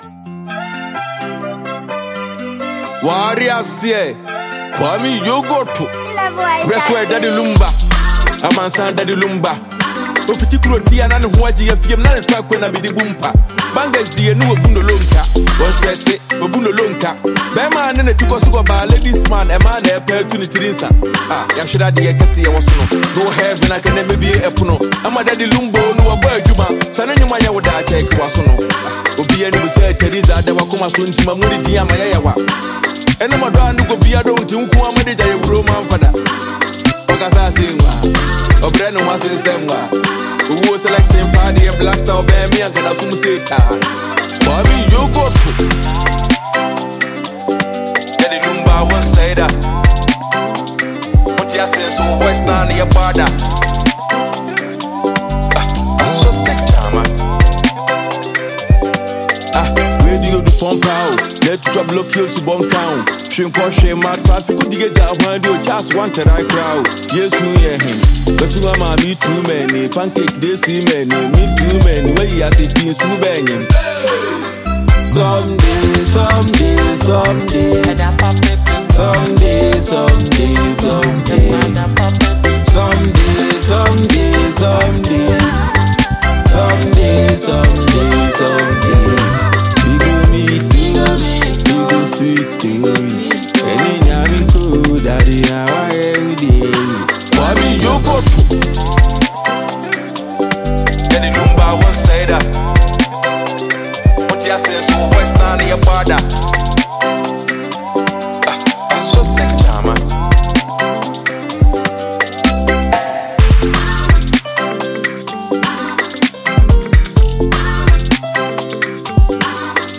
Enjoy this amazing Ghana tune.